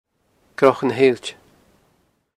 the informants pronounce the last element of this name: ale-itch-uh